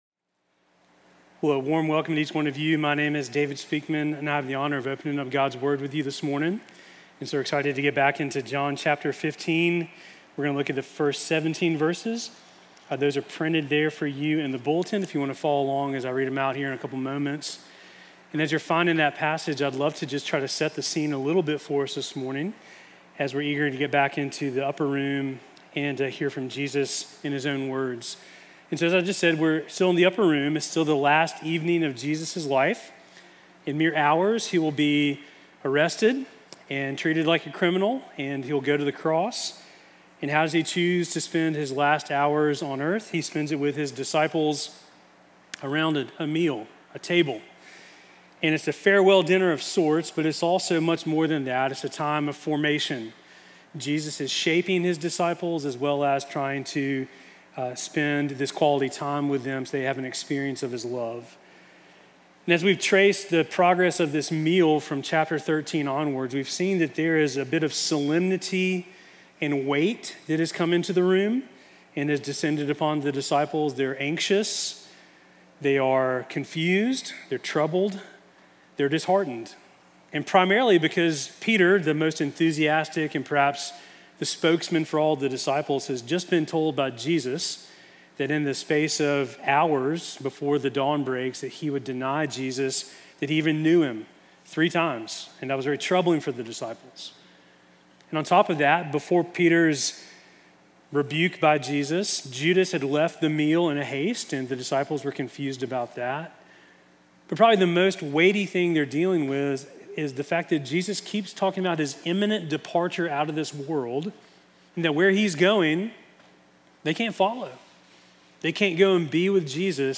Sermon from February 15